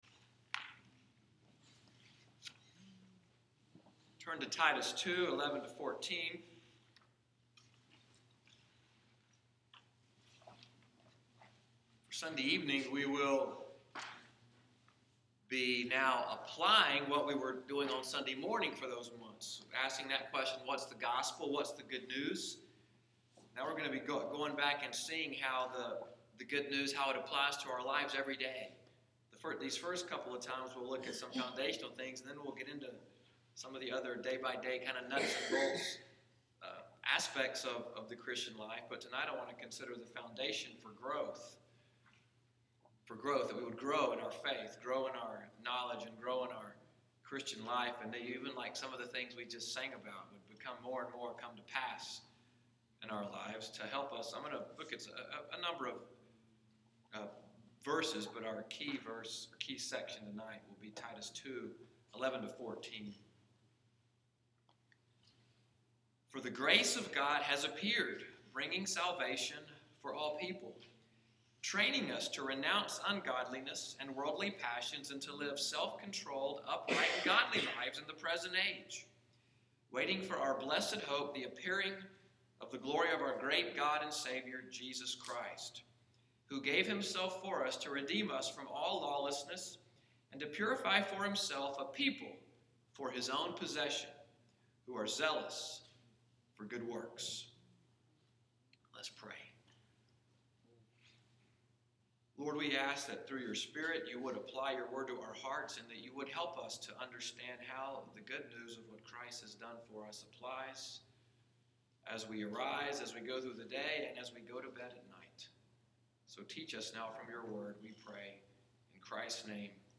New Covenant PCA: EVENING WORSHIP, January 10, 2016
Discipleship / Teaching Sermon: Grace is the Foundation for Christian Growth